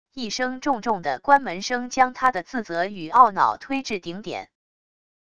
一声重重的关门声将他的自责与懊恼推至顶点wav音频